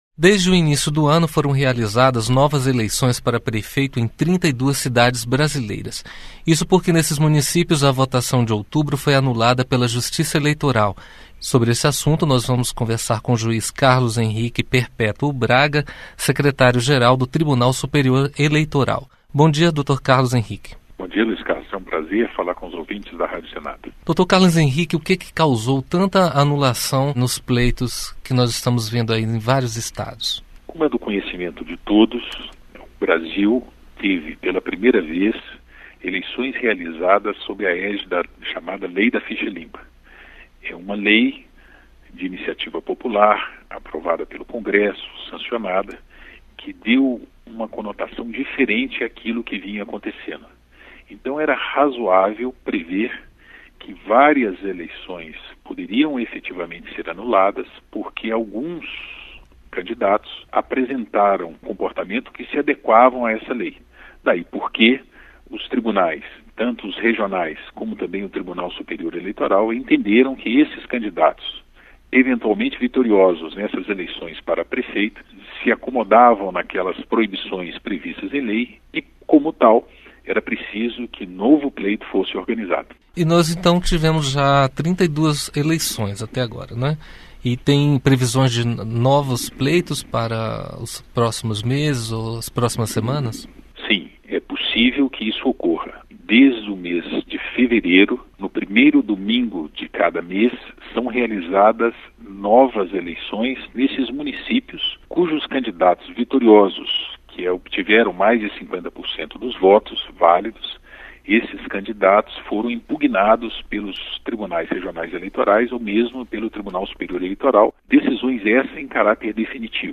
Entrevista: TSE faz novas eleições para prefeitos em 32 municípios Entrevista com o juiz eleitoral Carlo Henrique Braga, secretário-geral do Tribunal Superior Eleitoral.